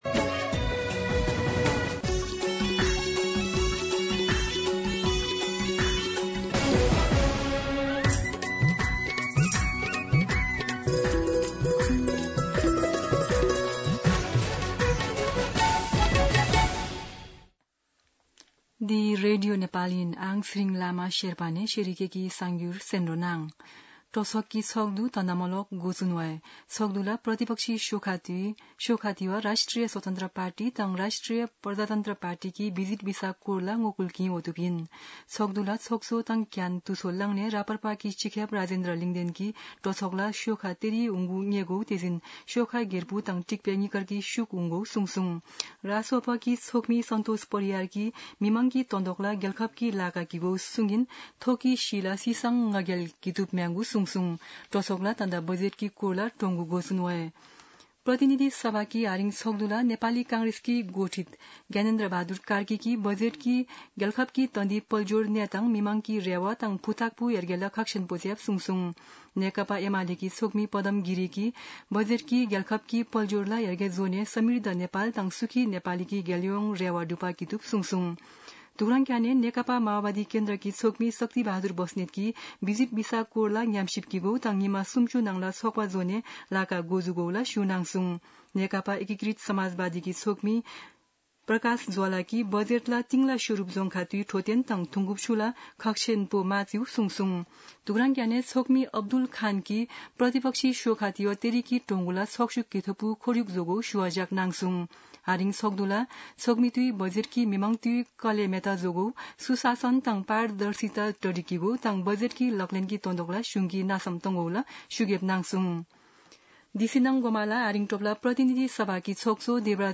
An online outlet of Nepal's national radio broadcaster
शेर्पा भाषाको समाचार : २ असार , २०८२